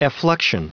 Prononciation du mot effluxion en anglais (fichier audio)
Prononciation du mot : effluxion